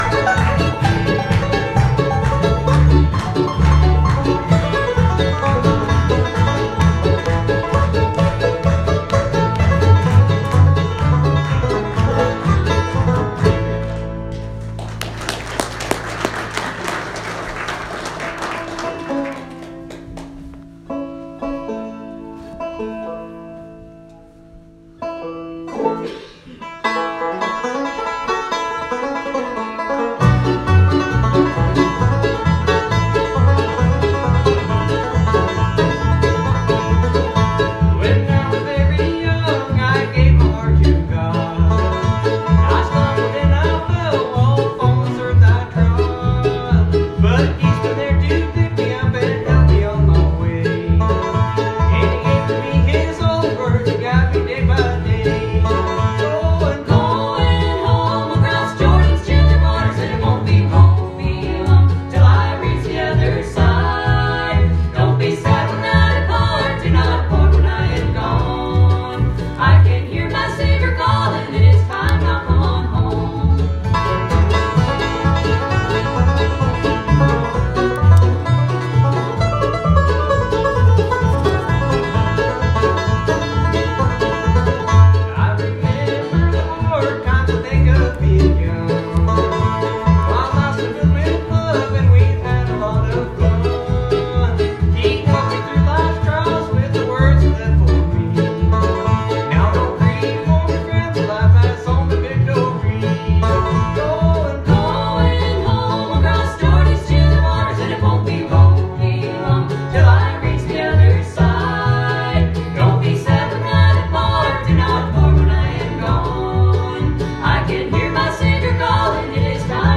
Special music by gospel bluegrass group The Patricks.